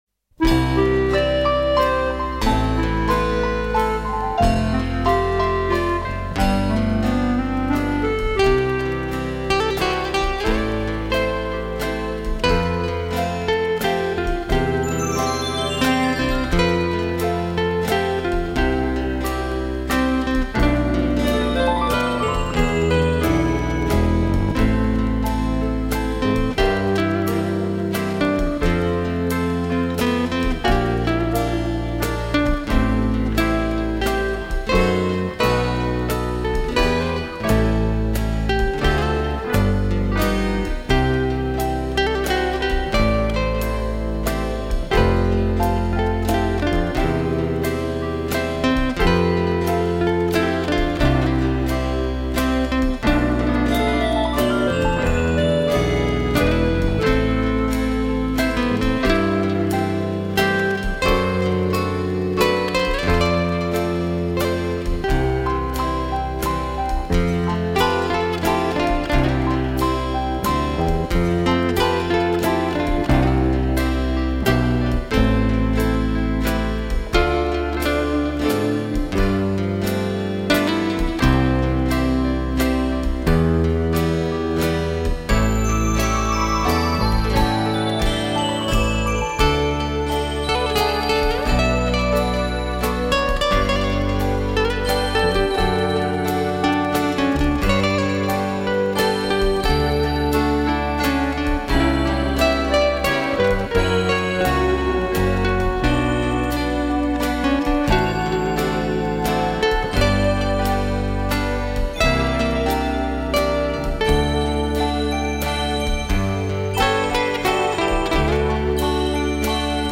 乐曲柔和，优雅，令人神往。